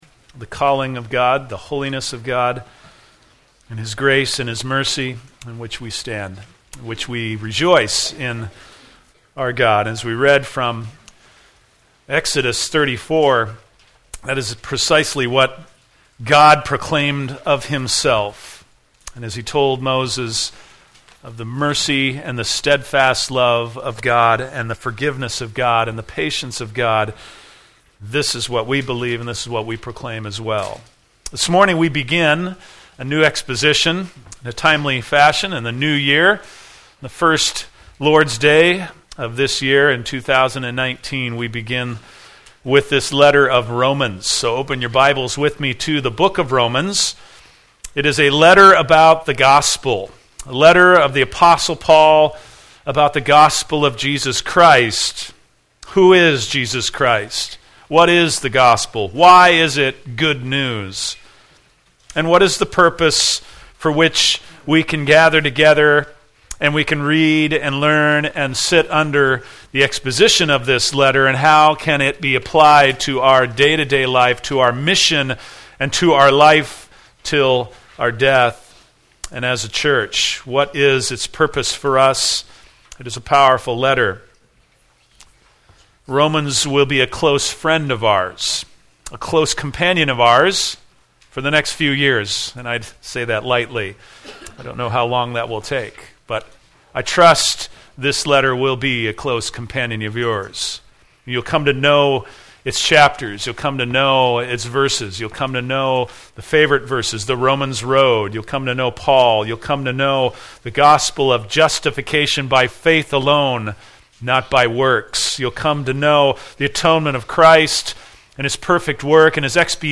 First message in a series on Romans.